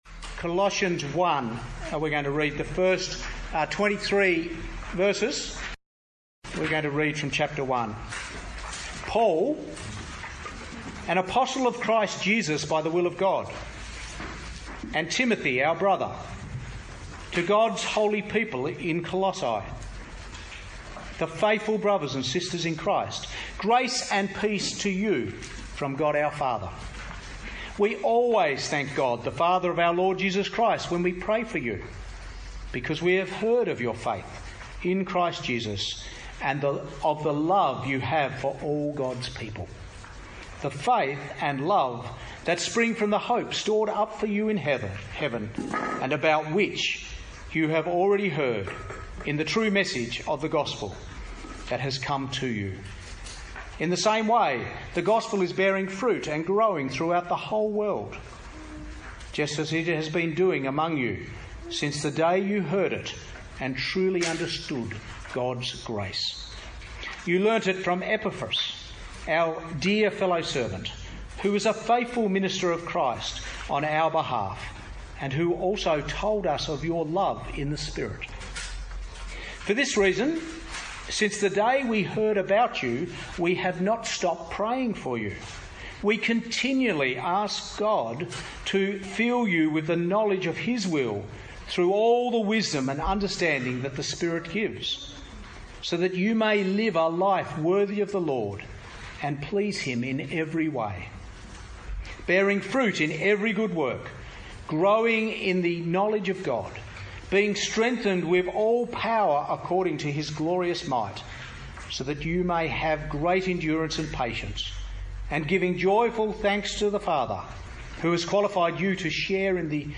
Summer Conference
Talk 1